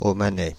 Ääntäminen
Synonyymit omani Ääntäminen France (Île-de-France): IPA: /ɔ.ma.nɛ/ Haettu sana löytyi näillä lähdekielillä: ranska Käännös 1. omāniešu {f} Suku: m .